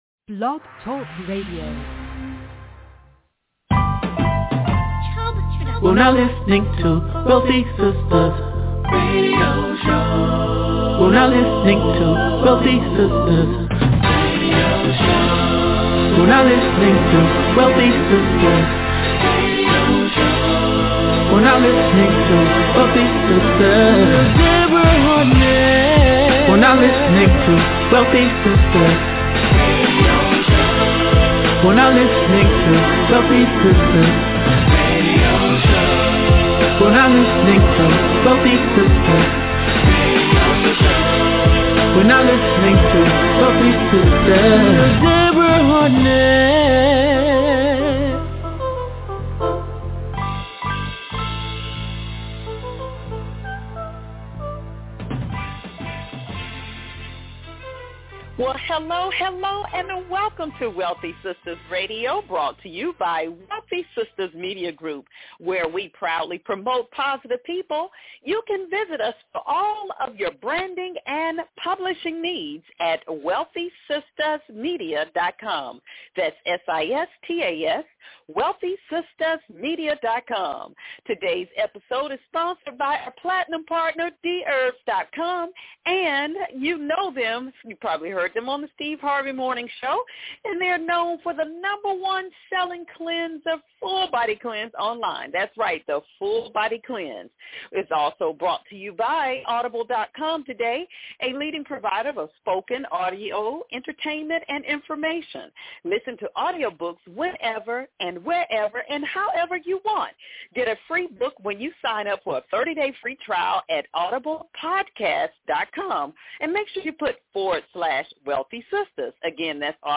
And is intertwined with some of the greatest R&B music of all time.